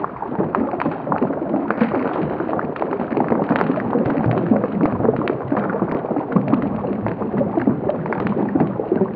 toxic_loop1.wav